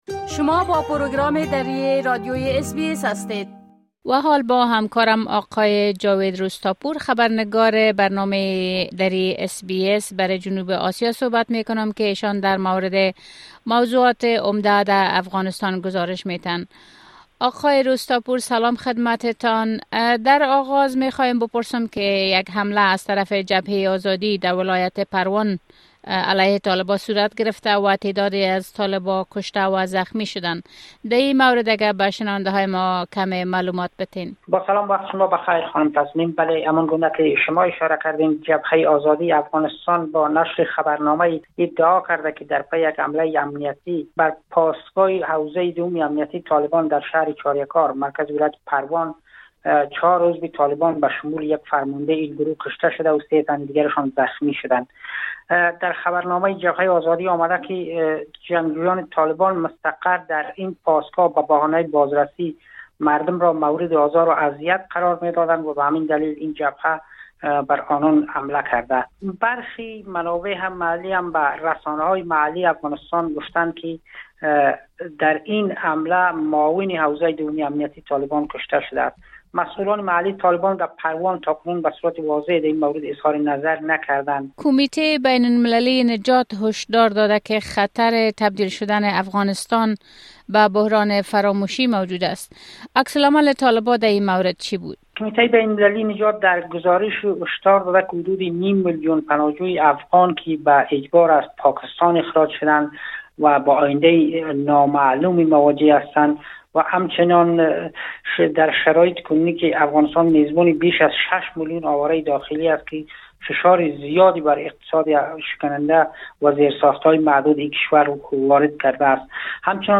گزارش كامل خبرنگار محلی ما، به شمول اوضاع امنيتى و تحولات مهم ديگر در افغانستان، را این‌جا بشنوید.